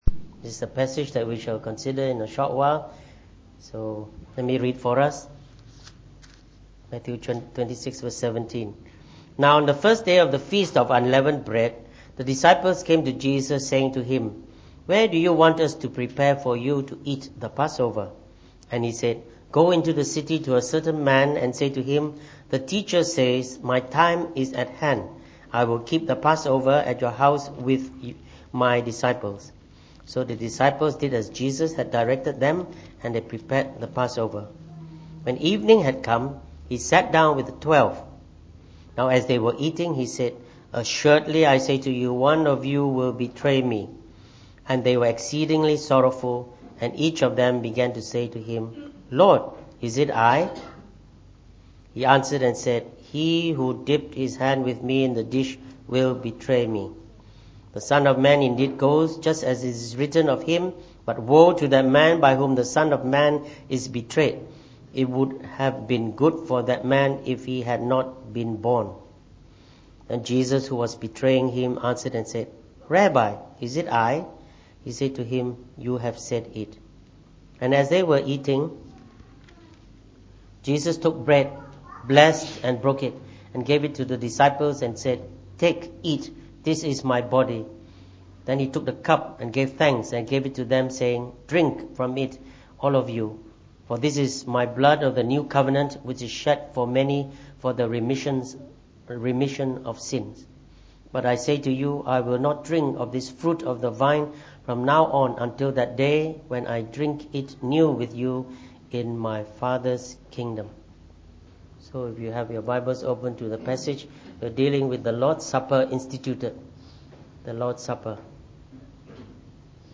delivered in the Evening Service